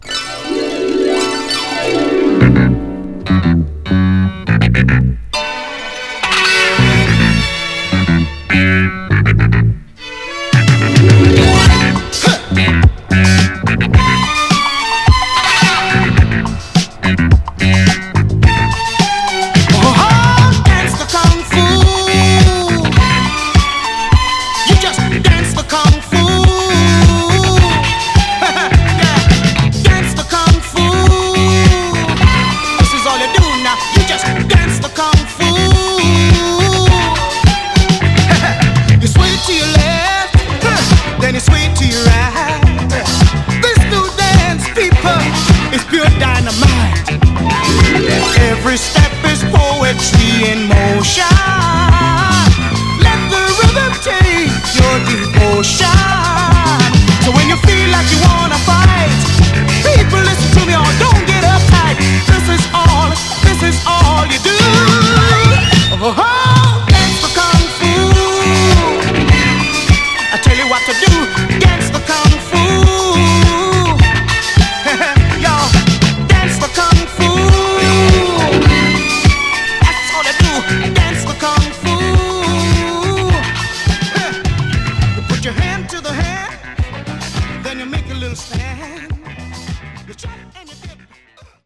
盤は細かい表面スレありますが、音への影響は少なくプレイ良好です。
※試聴音源は実際にお送りする商品から録音したものです※